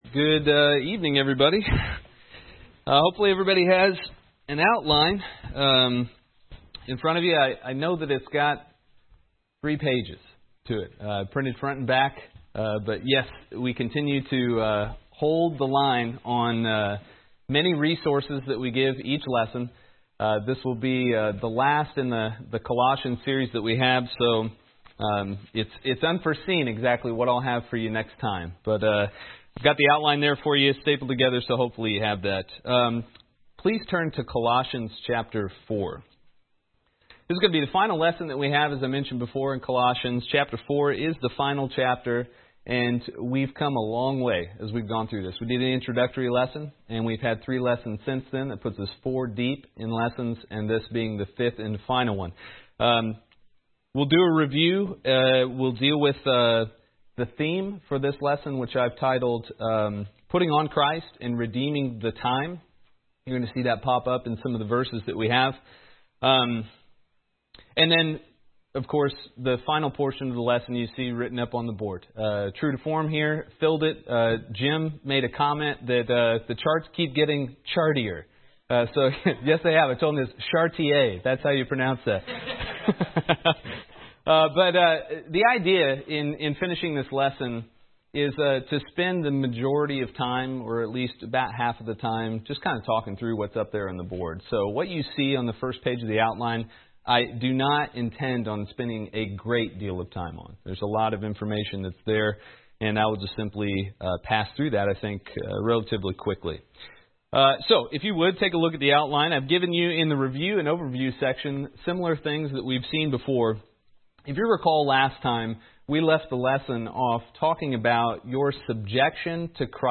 Download MP3 | Download Outline Description: This is the fifth, and final, lesson in a series of lessons summarizing the book of Colossians. In this lesson, we cover the fourth chapter of Colossians and learn about putting on Christ and redeeming the time.